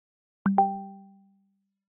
Звуки Gmail уведомлений скачать - Zvukitop